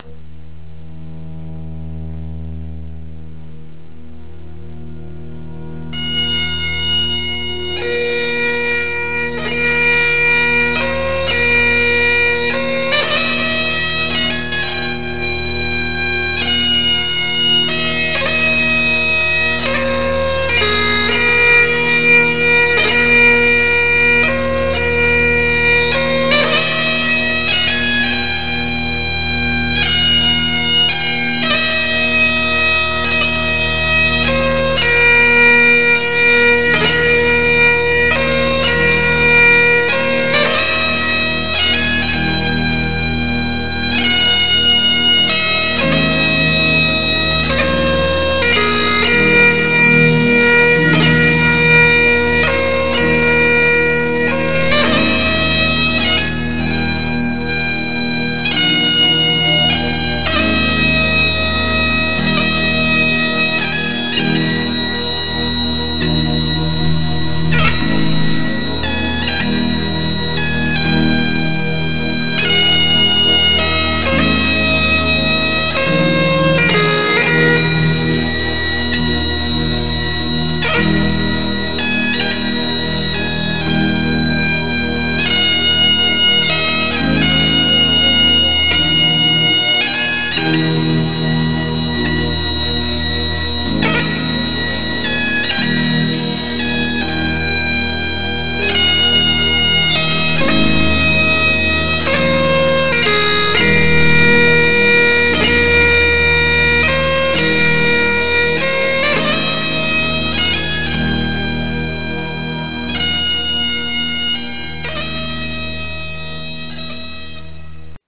The famous poem 'The Flowers Of The Forest' was written in the mid - 18th century to commemorate the battle of Flodden in 1513. It was made in to a very haunting pipe tune which is traditionally played at military funerals.